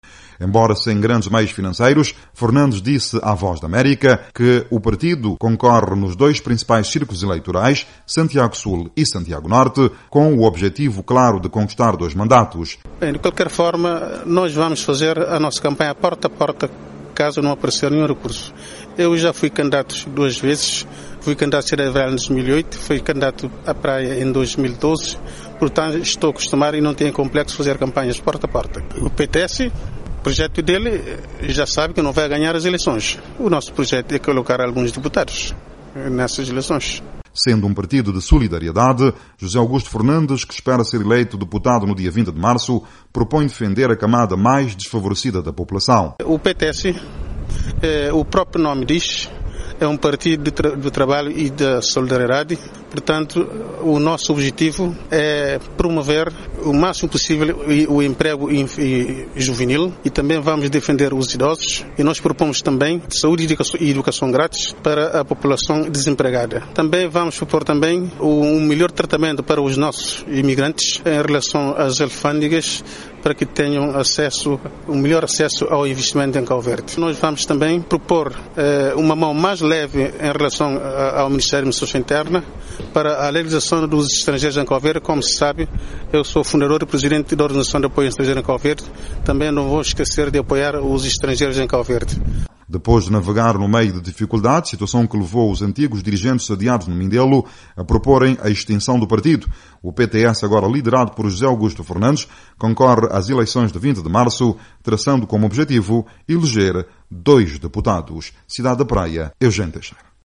Eleições em Cabo Verde: Líder do PTS fala à VOA 2:00